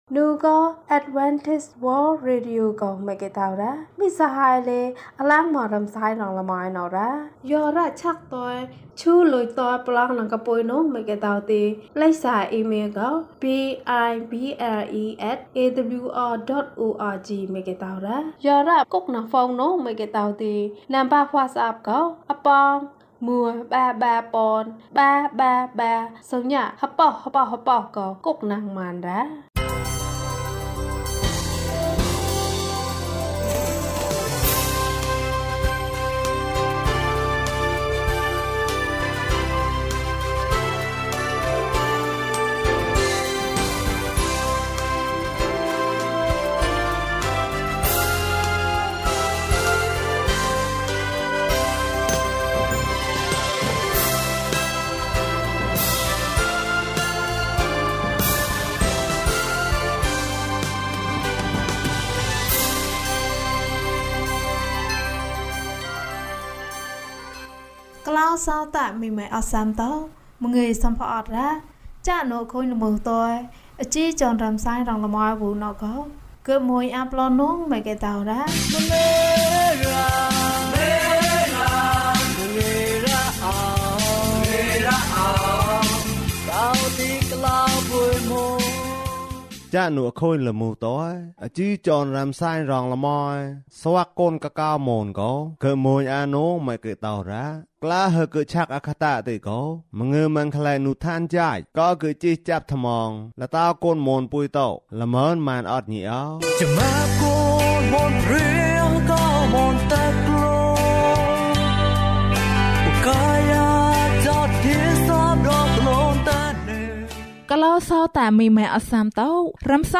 အချစ်ဆုံးသူငယ်ချင်း။ အပိုင်း ၁ ကျန်းမာခြင်းအကြောင်းအရာ။ ဓမ္မသီချင်း။ တရားဒေသနာ။